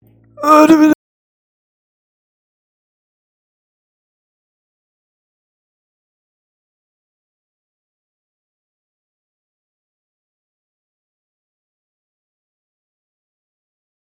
dorfaeltesterspeak.mp3